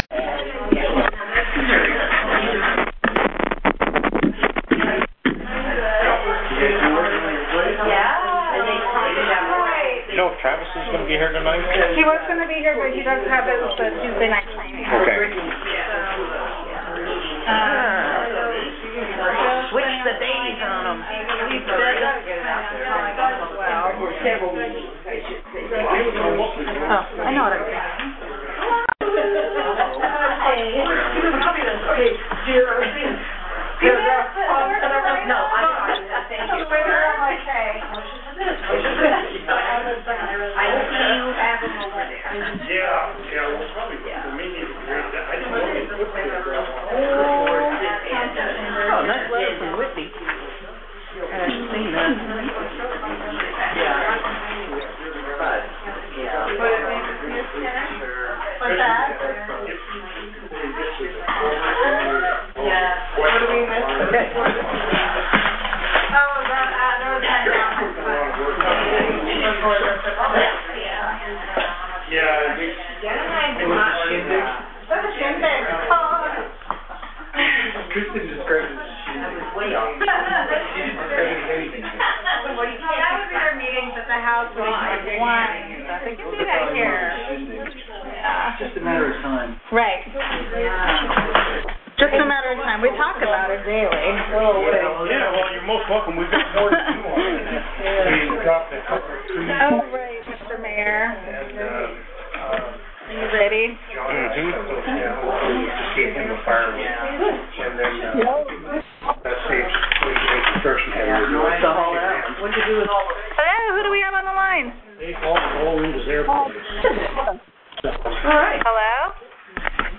City Council General Meeting | City of Gustavus Alaska